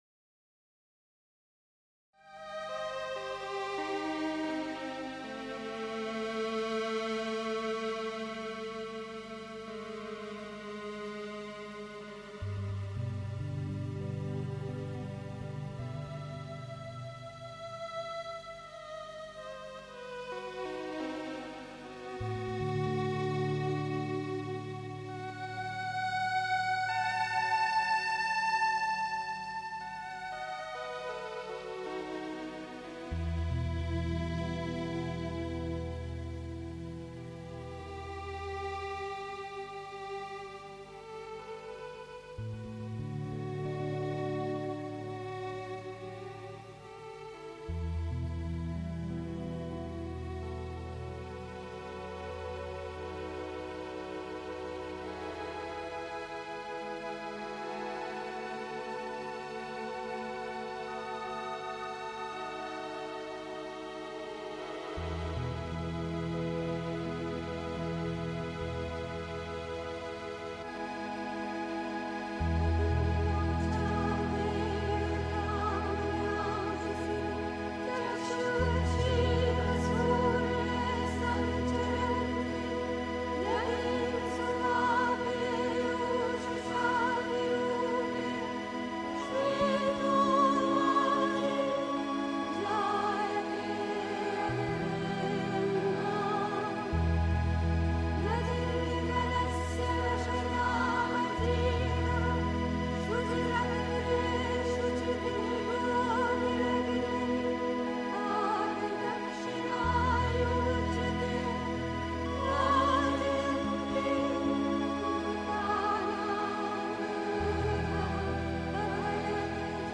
Pondicherry. 2. Der Schlüssel des Yoga (Sri Aurobindo, Essays Divine and Human, p. 365) 3. Zwölf Minuten Stille.